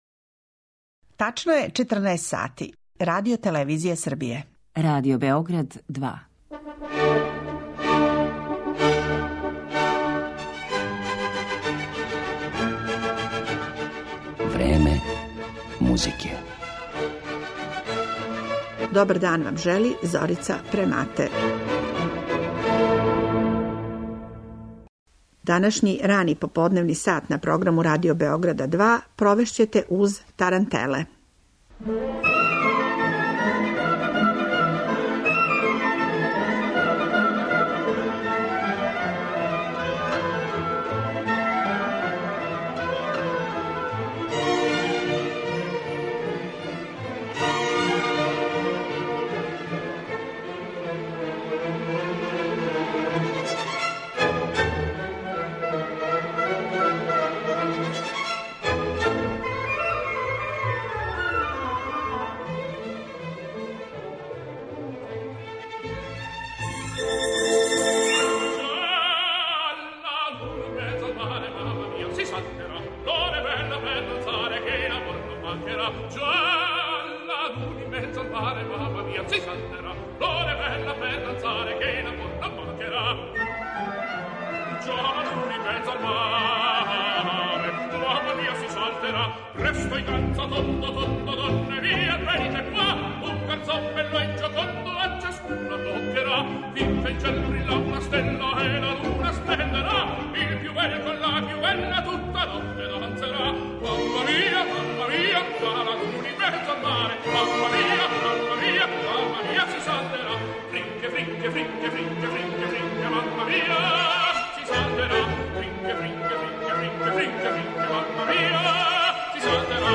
избор стилизација овог жустрог плеса са југа Апенинског полуострва